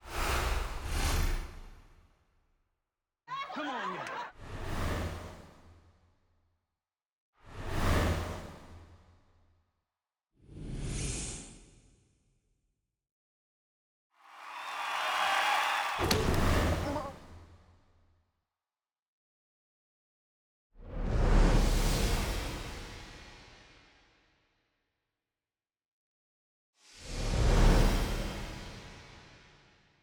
UH_S2_LAUNCH_30_CABLE_ST_SFX.wav